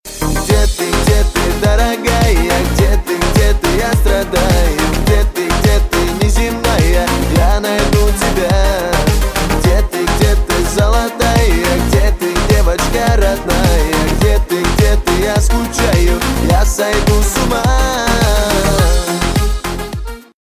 • Качество: 321, Stereo
поп
мужской вокал